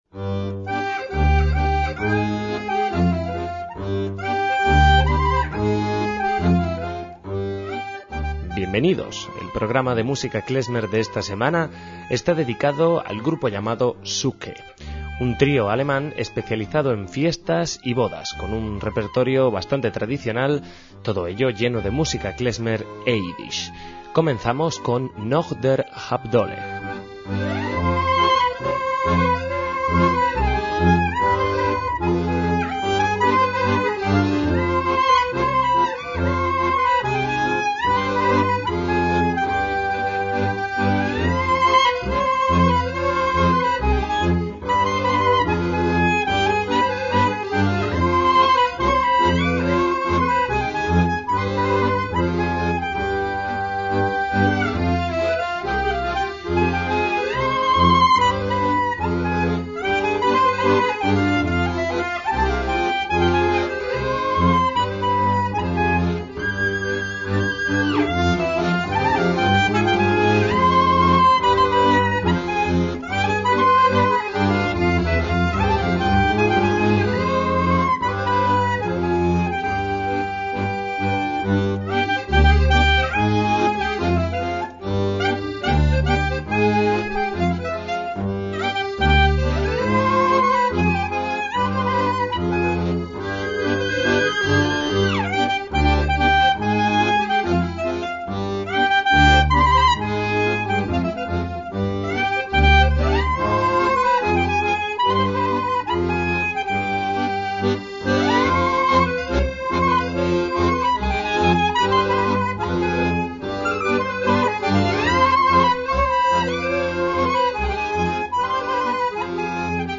MÚSICA KLEZMER
trío de música klezmer con músicos europeos
clarinete
acordeón
contrabajo y guitarra